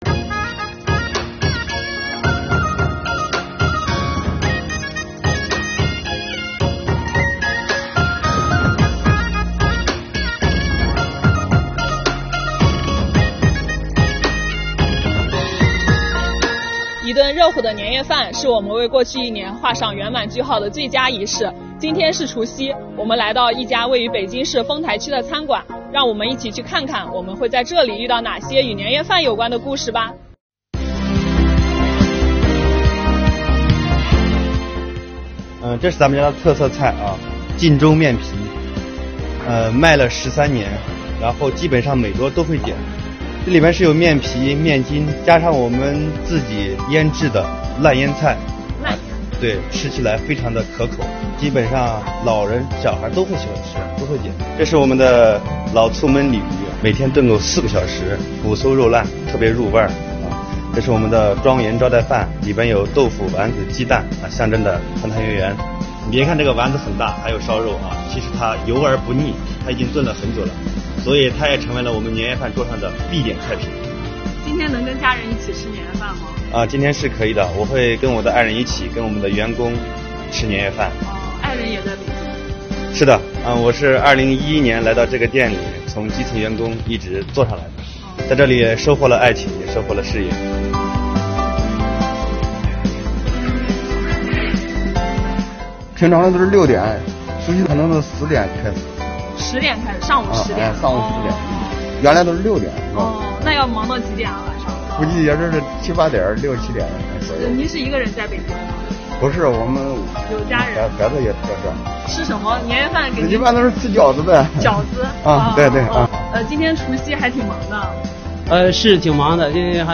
除夕这天，中国税务报“新春走基层”采访组走进位于北京市丰台区的餐馆晋风庄园。在这里，我们遇见了做年夜饭的人，吃年夜饭的人，送年夜饭的人……让我们一起，聆听这暖意浓浓的烟火故事。